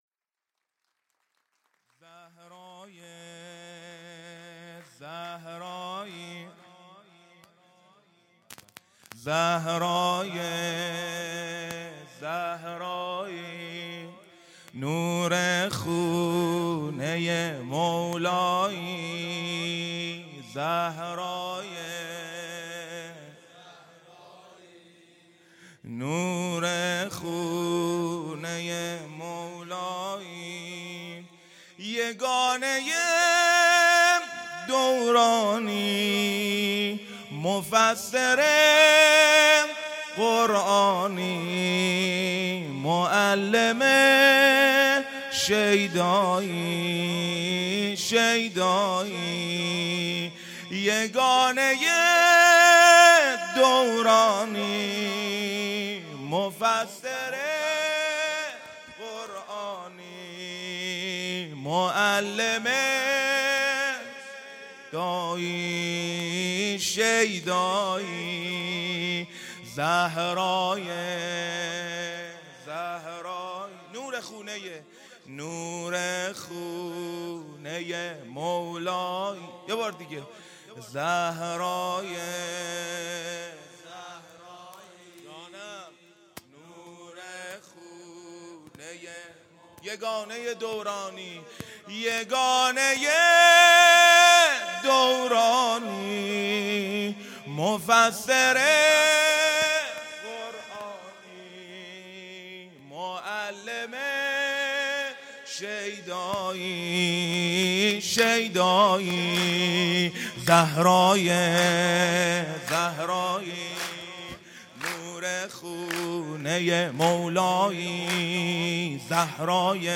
ولادت حضرت زینب